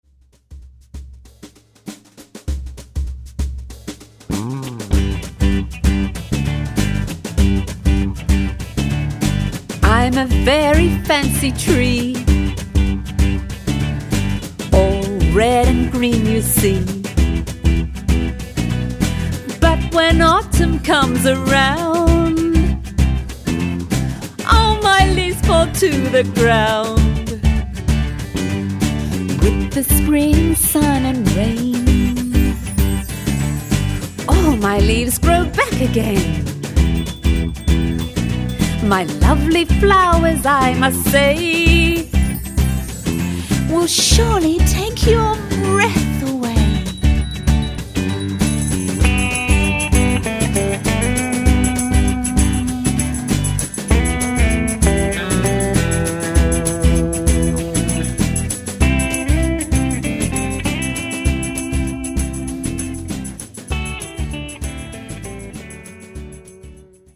A 2 part vocal arrangement with guitar/piano/accompaniment.
primary mixed choir
children's music, Australian music